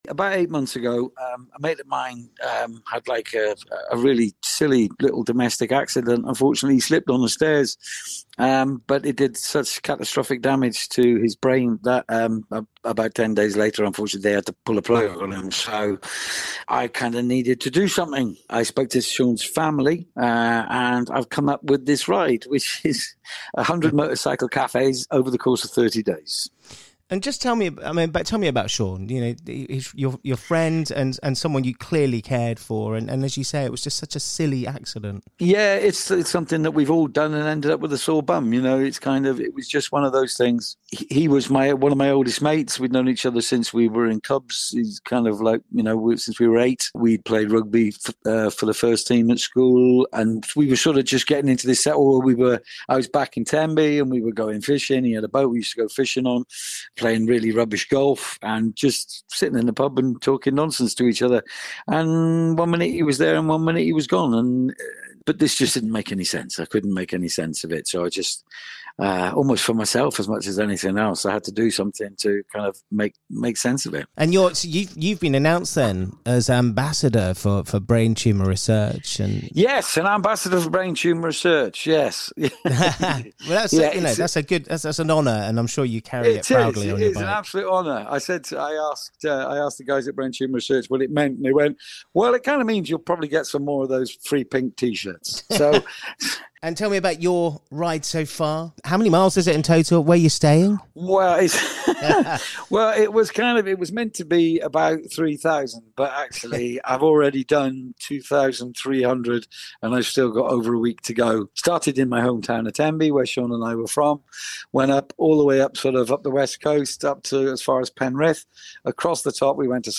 PRESS PLAY to listen to our conversation with him...